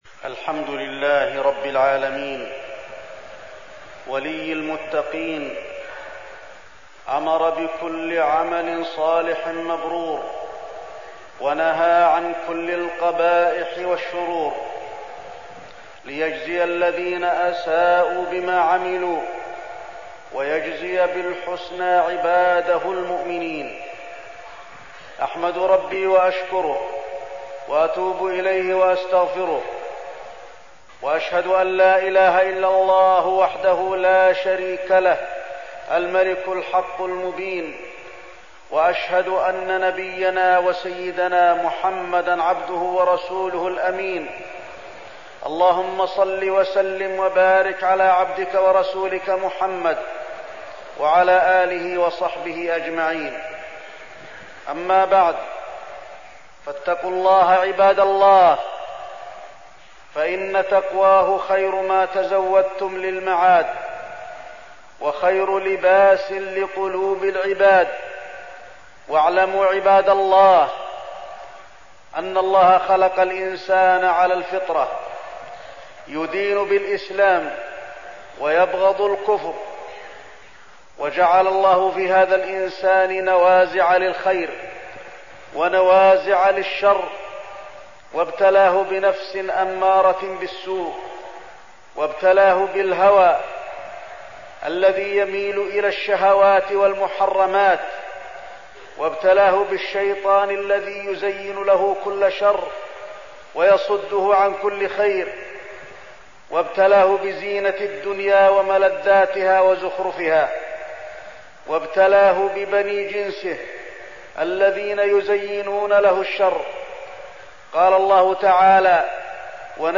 تاريخ النشر ٢ جمادى الآخرة ١٤١٨ هـ المكان: المسجد النبوي الشيخ: فضيلة الشيخ د. علي بن عبدالرحمن الحذيفي فضيلة الشيخ د. علي بن عبدالرحمن الحذيفي مخالفة الهوى